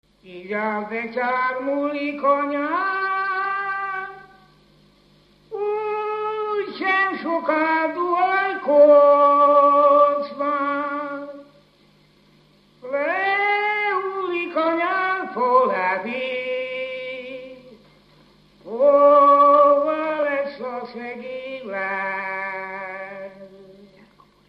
Dunántúl - Somogy vm. - Karád
ének
Stílus: 1.2. Ereszkedő pásztordalok
Szótagszám: 8.8.8.8
Kadencia: 8 (5) b3 1